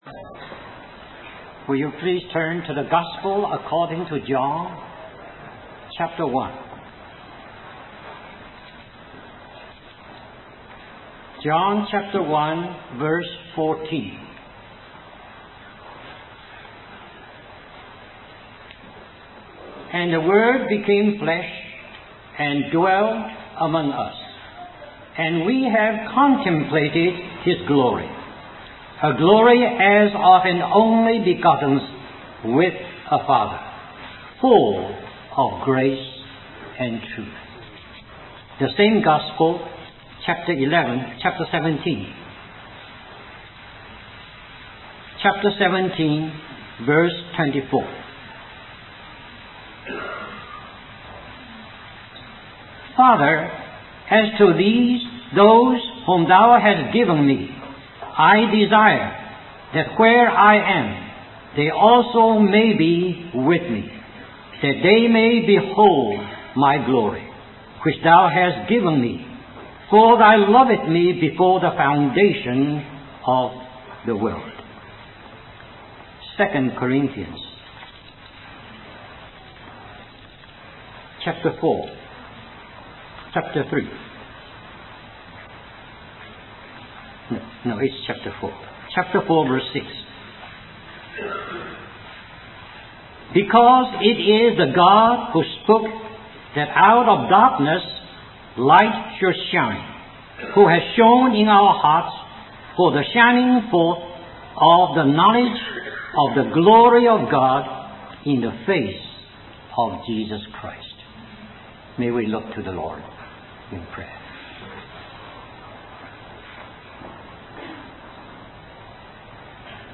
In this sermon, the preacher emphasizes the perfection and intense excellency of Jesus Christ.